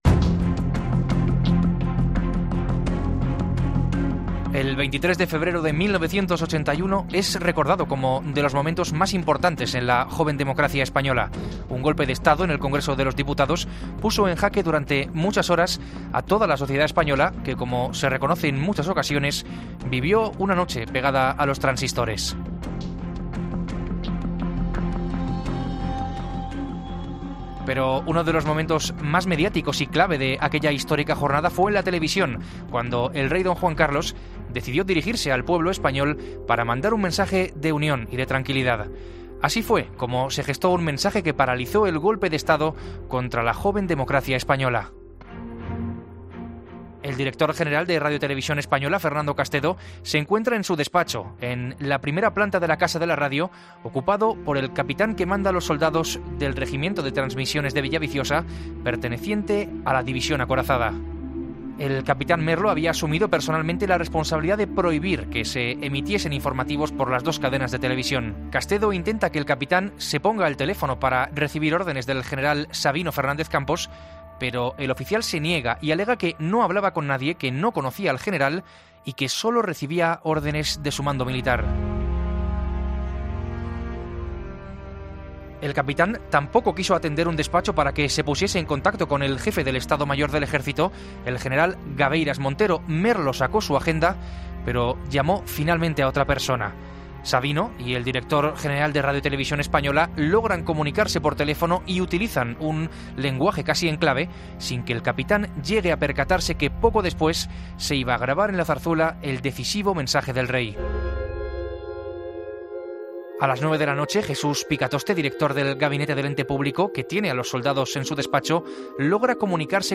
En el siguiente relato sonoro repasamos como se gestó aquel momento televisivo que ya forma parte de la historia contemporánea de España.